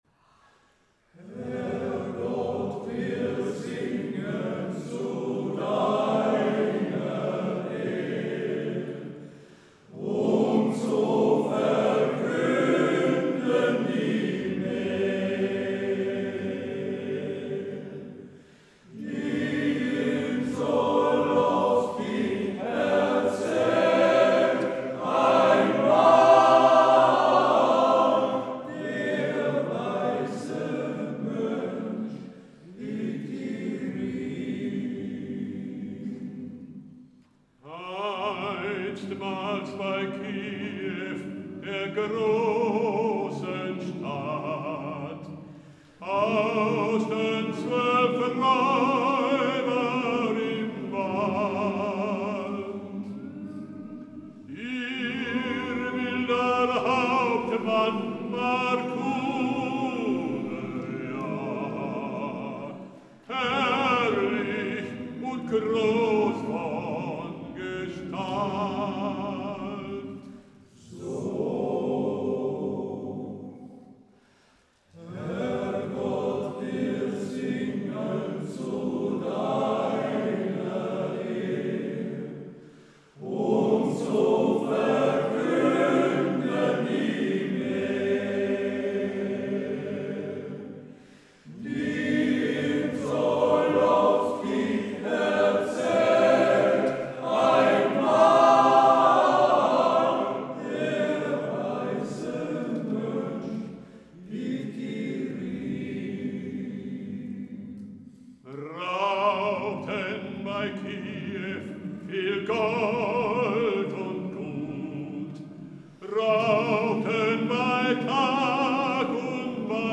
Sängerkreiskonzert 15. Juli 2023